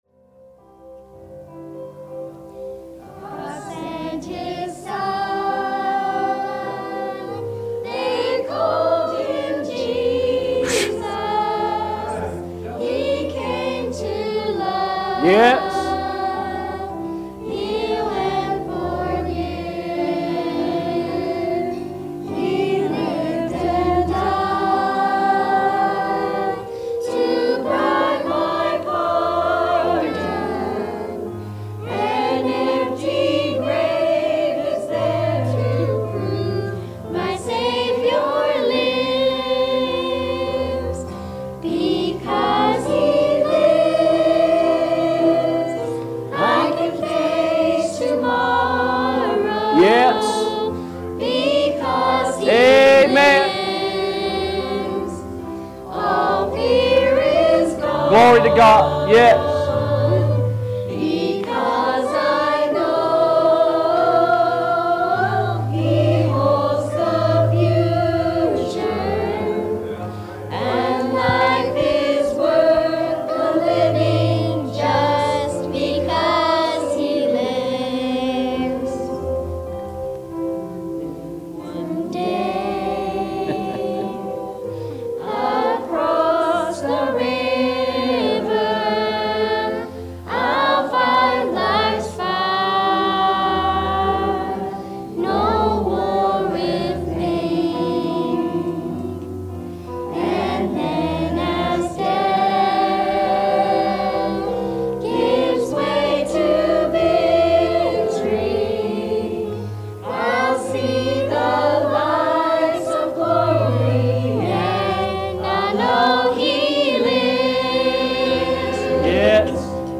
Berean Baptist Resurection Sunday
Track 8 - Berean Baptist Womens Choir - Because He Lives